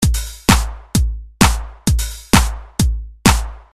Tag: 130 bpm Electronic Loops Drum Loops 636.10 KB wav Key : Unknown